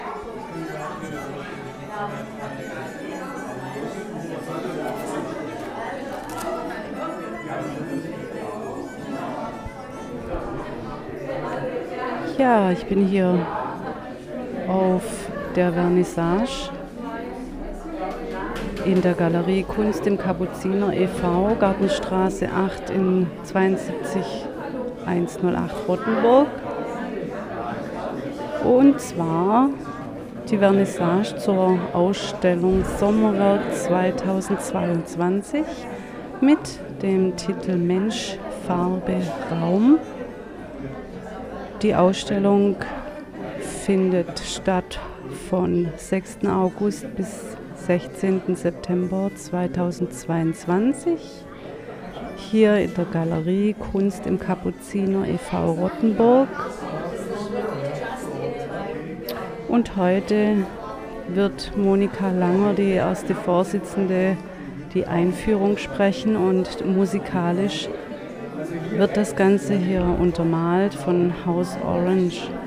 Anmoderation für den Beitrag: Sommerwerkausstellung 2022, Galerie KUKA e.V. Rottenburg